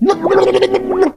otis_hurt_vo_05.ogg